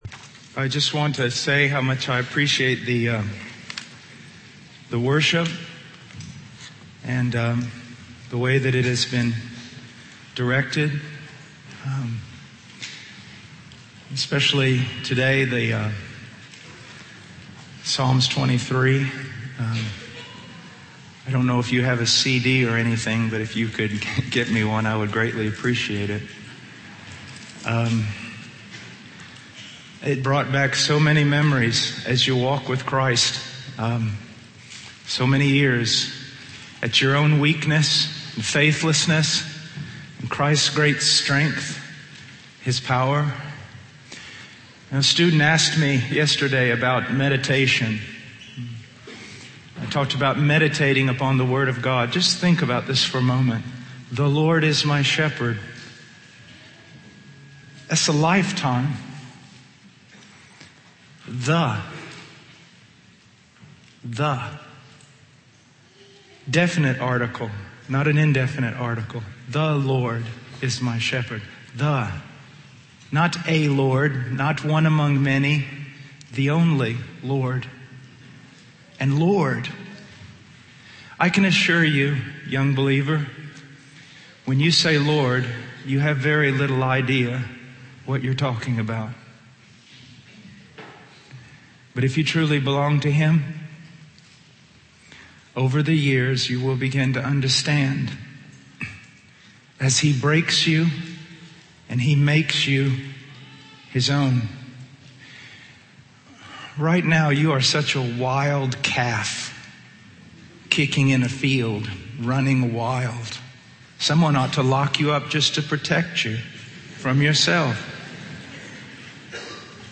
In this sermon, the preacher uses an illustration to emphasize the importance of justice in understanding the gospel.